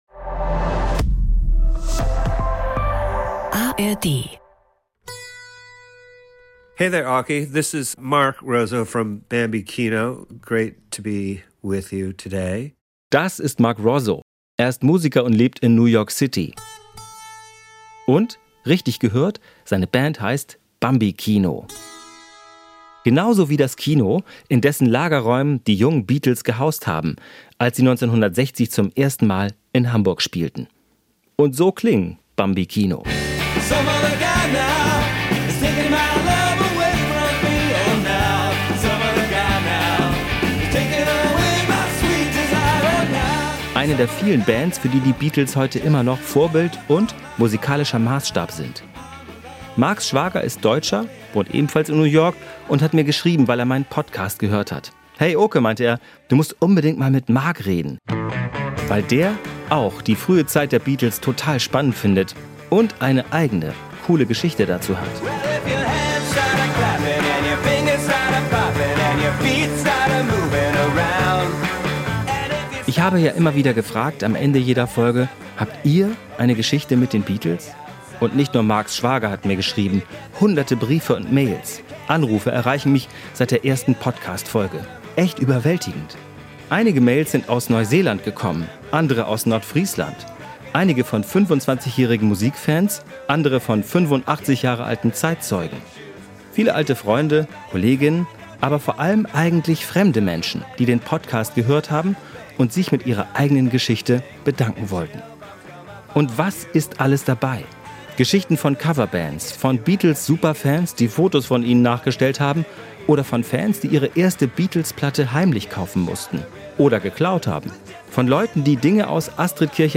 Ein Podcast mit viel Musik, O-Tönen von Zeitzeugen und seltenem Archivmaterial.